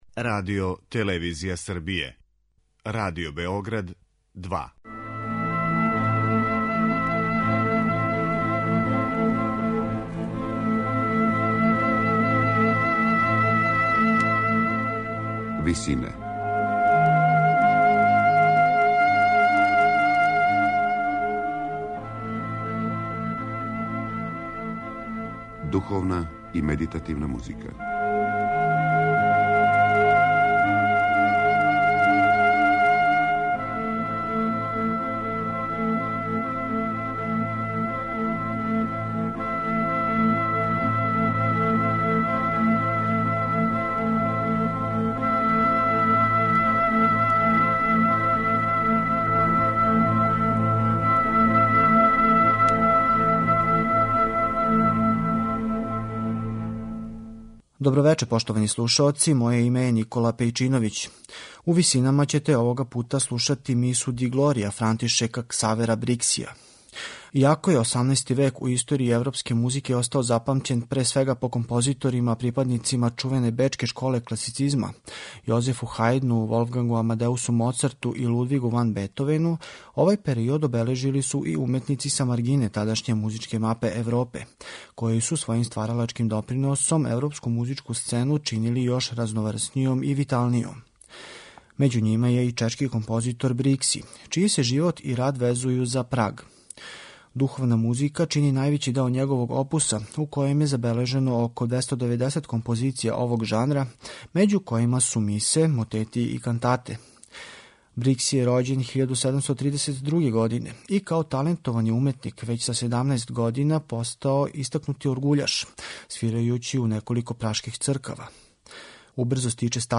Овај чешки композитор живео је од 1732. до 1771. године и у свом опусу је комбиновао барокне и класичарске стилске утицаје.